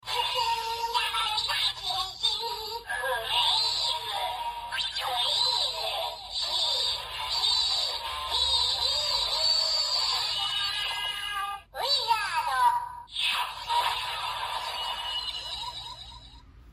法爷饱藏音效.MP3